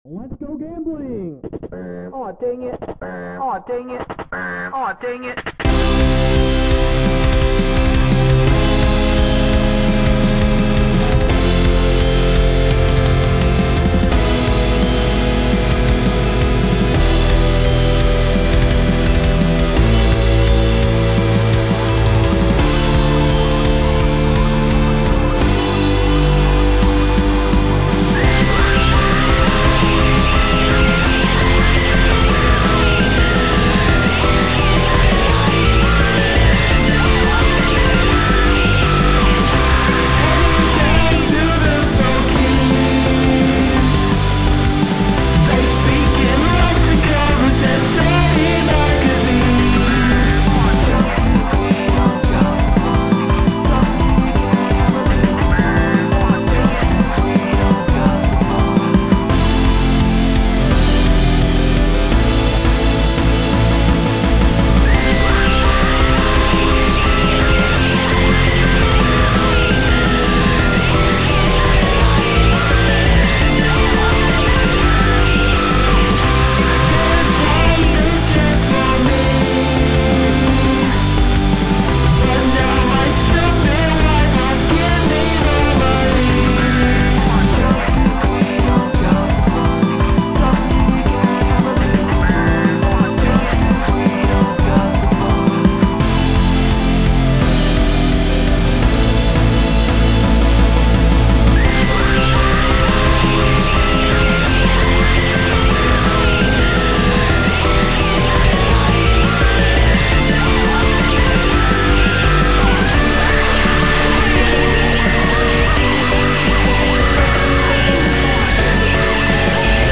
Bedroom/synth scramz